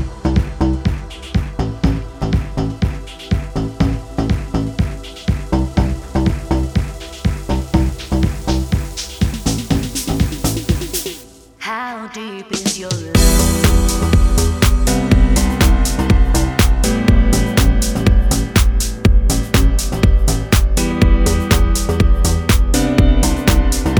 no Backing Vocals Dance 3:29 Buy £1.50